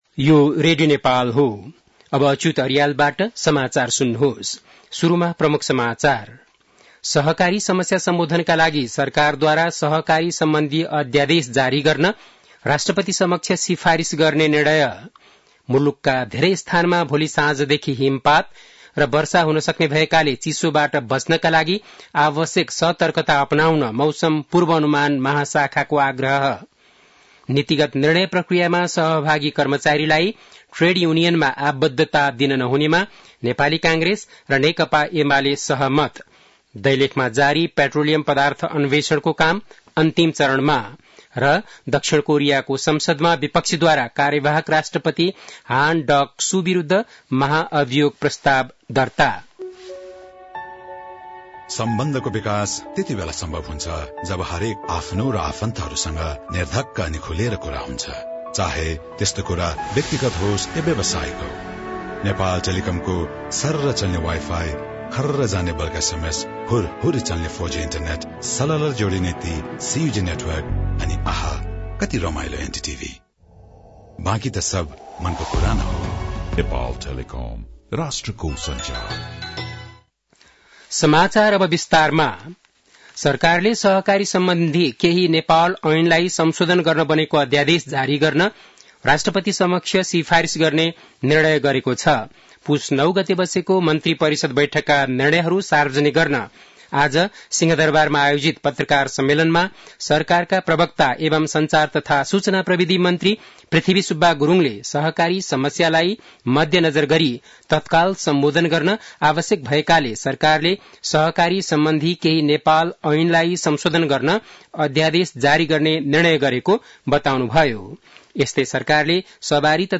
An online outlet of Nepal's national radio broadcaster
बेलुकी ७ बजेको नेपाली समाचार : १२ पुष , २०८१